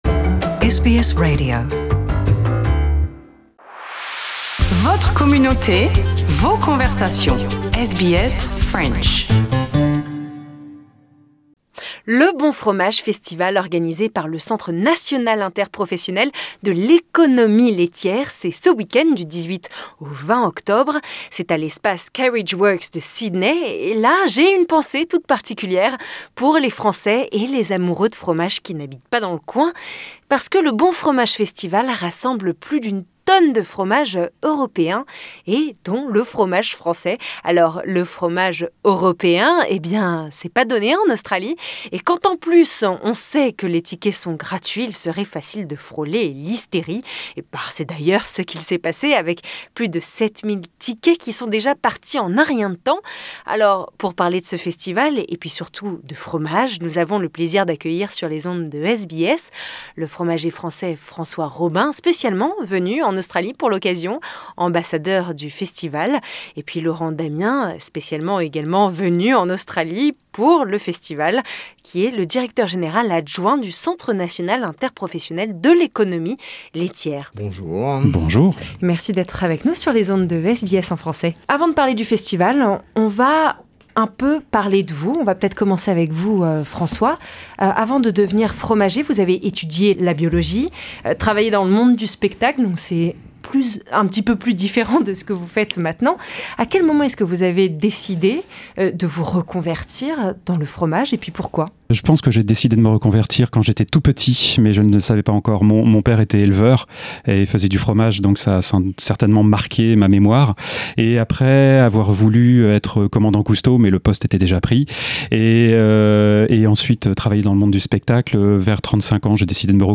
Pour en parler avec nous dans nos studios